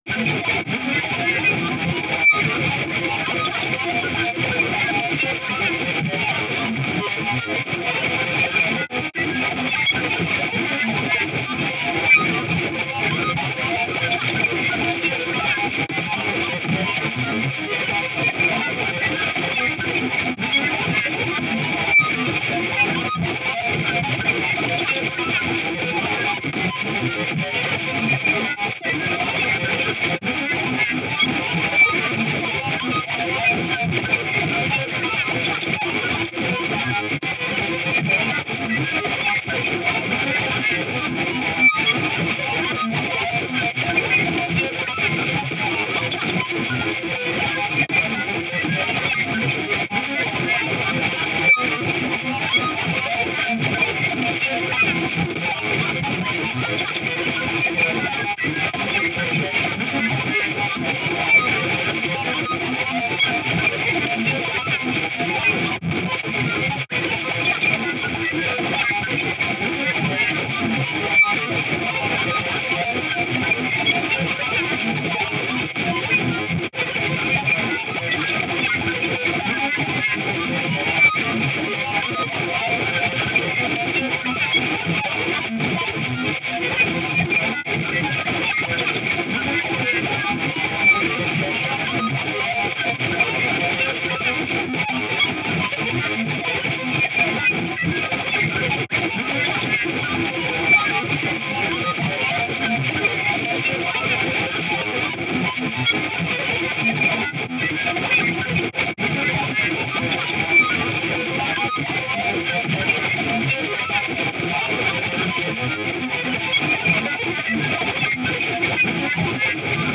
久々のギターらしいギターの音が聴けるアルバムです。
作曲されたオーケストレーション作品です。
超音波帯域の無数の細かな鋭い針が、脳に突き刺さり続ける。
轟音と静寂、激しさと安らかさ、覚醒と酩酊が同時にそこに在るような。